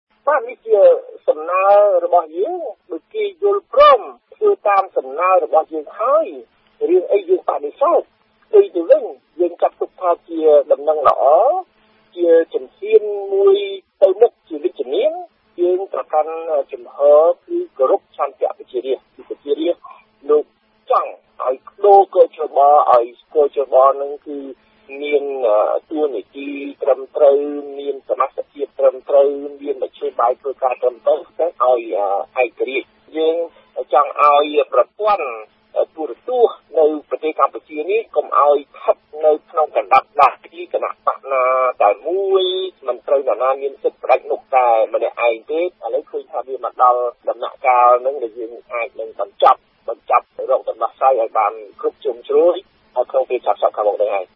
ប្រសាសន៍ របស់ លោក សម រង្ស៊ី
Sound_Sam_Rainsy.mp3